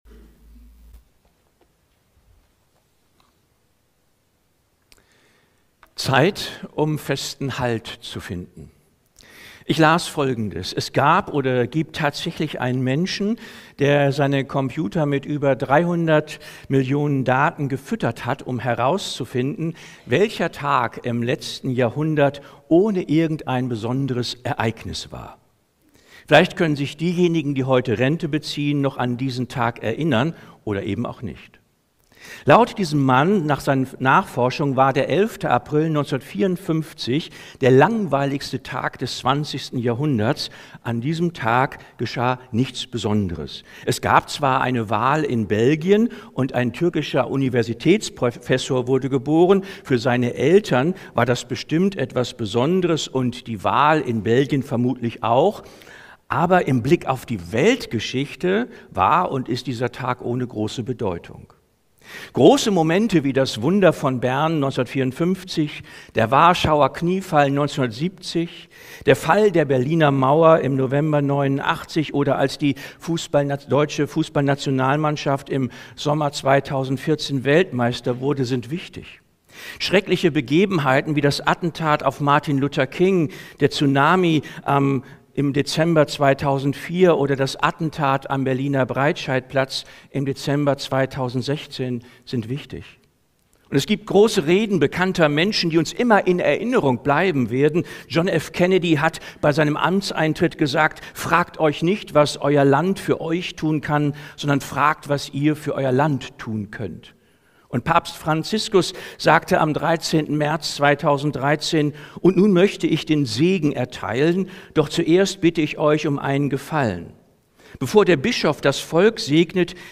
Predigten aus der Baptistengemeinde Leer